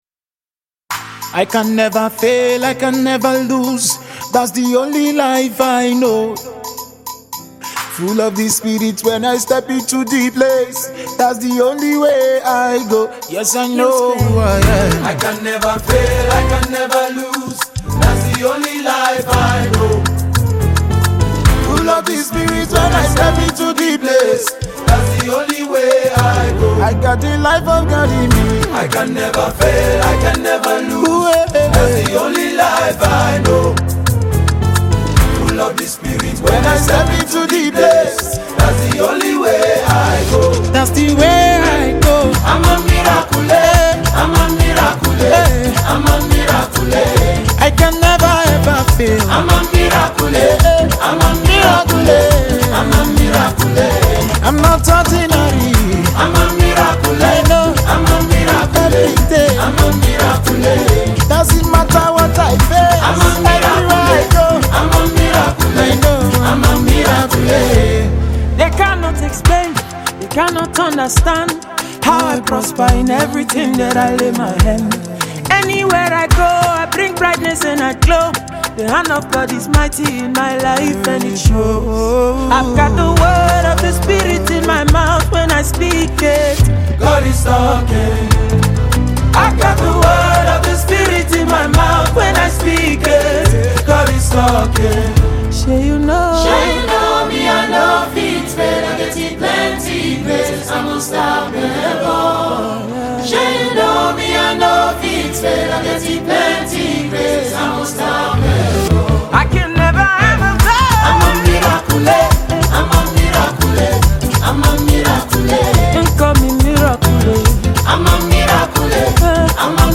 Nigerian celebrated and multiple award winning gospel singer
praise single